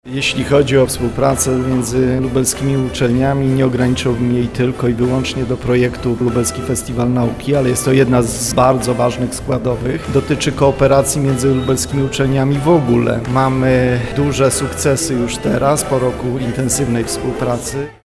Potencjał naukowo-badawczy mamy ogromny. Warto z niego korzystać, nie trzeba uciekać z Lublina – mówi rektor Uniwersytetu Marii Curie-Skłodowskiej Radosław Dobrowolski: